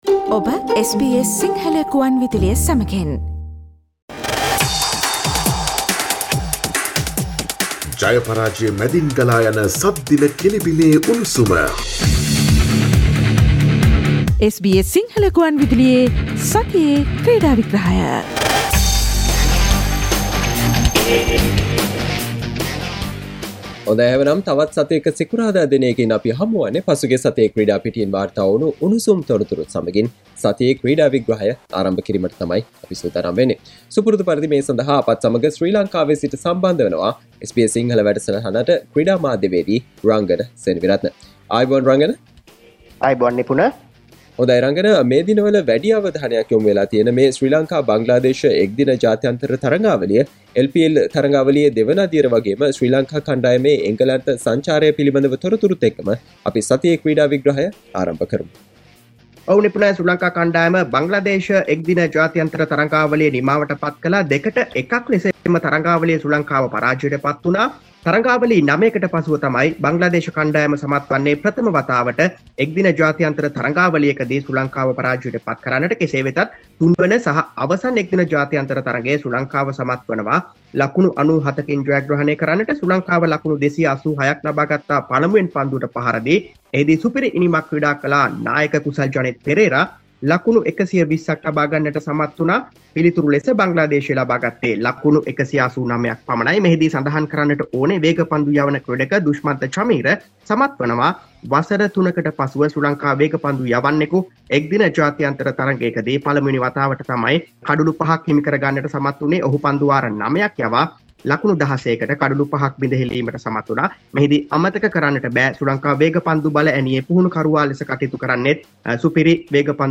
SBS Sinhala Sports Wrap with Sports Journalist